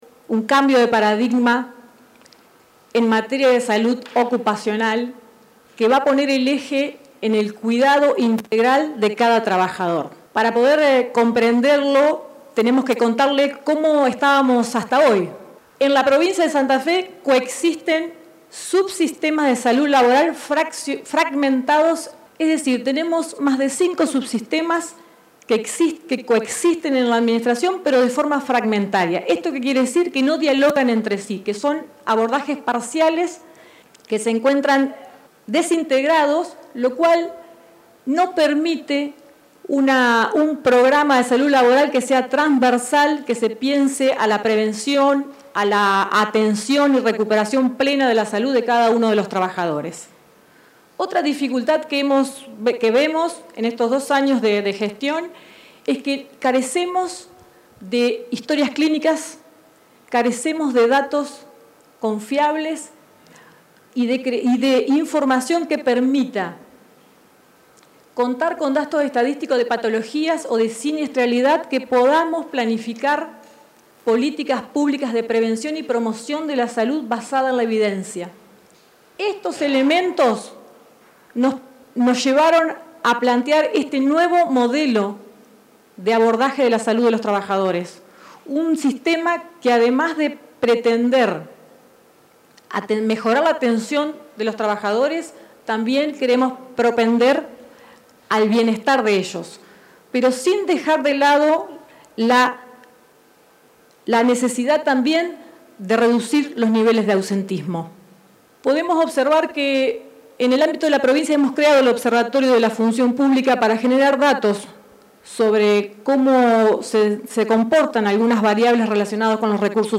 Fue este martes mediante una conferencia de prensa en la Casa de Gobierno de Santa Fe.